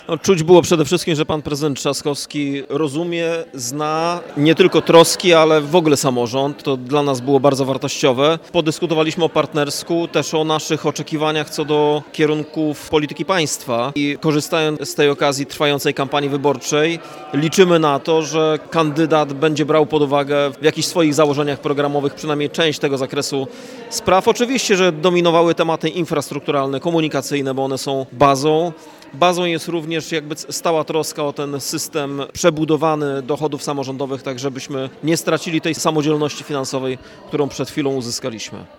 Omawiane były kwestie związane z inwestycjami, ochroną zdrowia, oświatą czy finansowaniem zadań samorządów. Spotkanie podsumowuje Rafał Zając, prezydent Stargardu